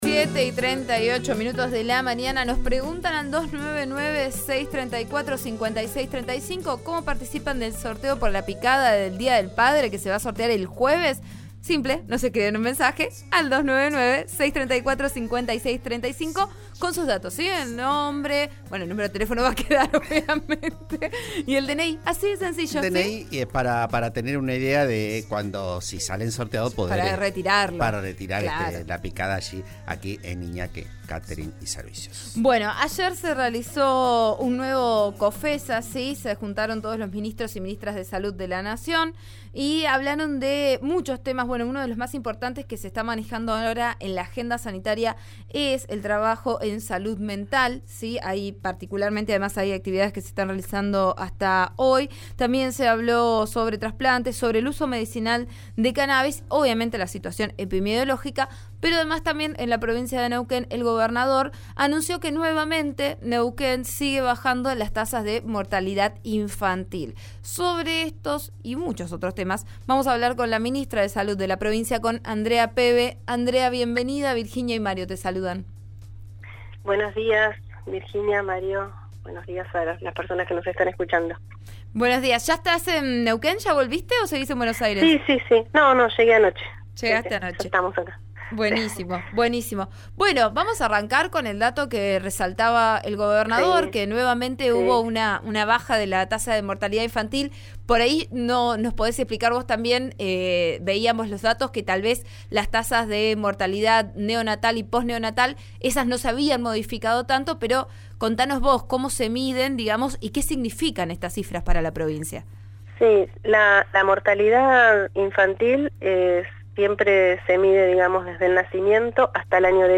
En dialogo con Vos a Diario, por RN Radio, la funcionaria indicó que se va a abrir el convenio para revisar y ver modificaciones y mejoras que se puedan hacer, pero que la demora de la definición de los paritarios gremiales retrasó la tarea.